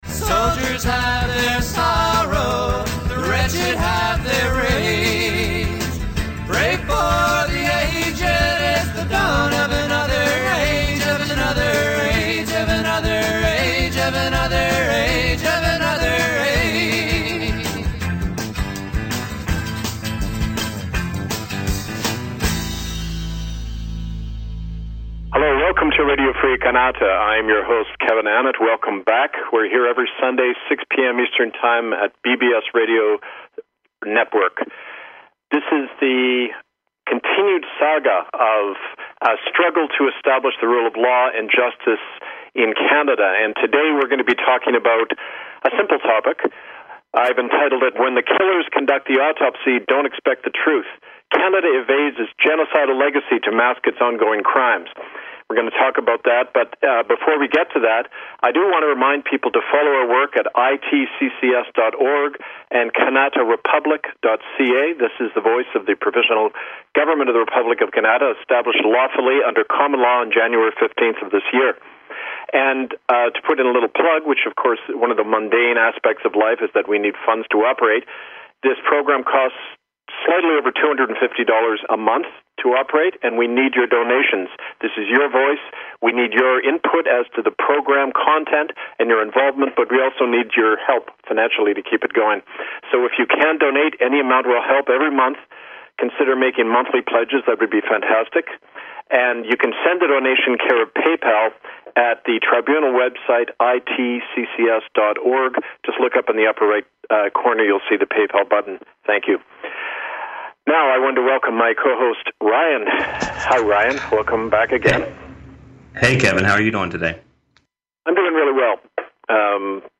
Robert Jackson, Chief American Prosecutor at the Nuremberg Trials, 1946 Why Canada is Dissolved: The government-church admits Genocide An Exclusive interview Canada publicly admitted this past week that it and its partner churches committed genocide on generations of indigenous children and caused "thousands" of deaths in the Indian residential school system.